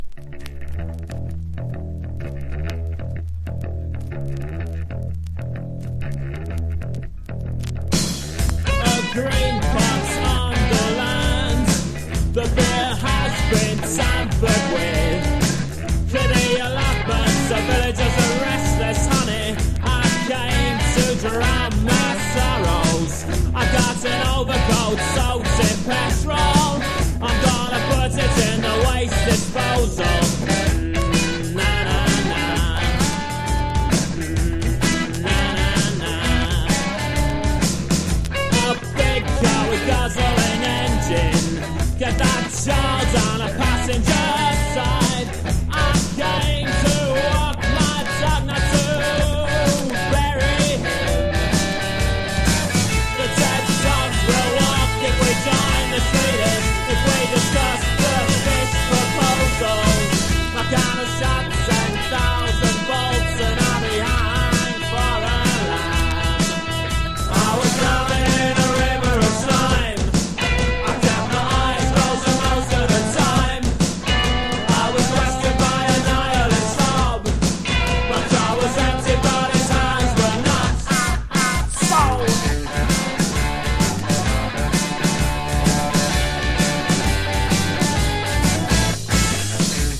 80’s ROCK / POPS